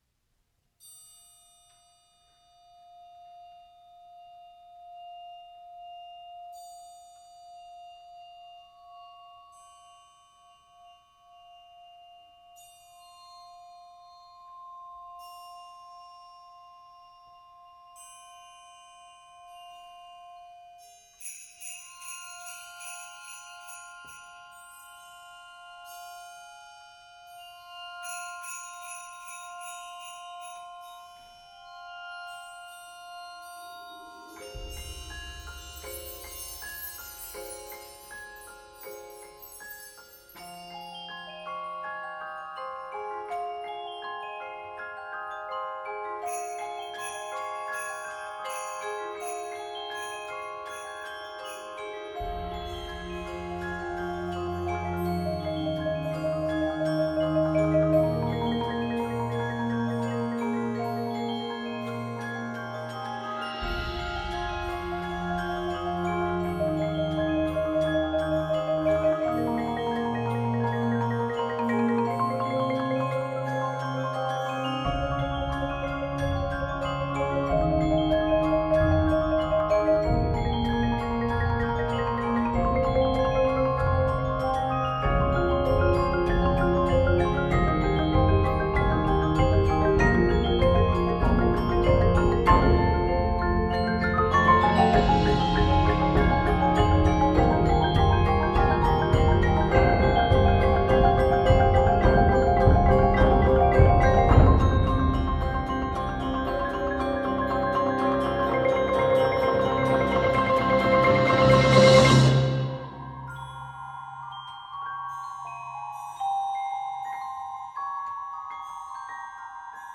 Voicing: 18-24+ Players